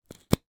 Звуки клея
Сняли крышку с клея